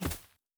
Tree Hit_2.wav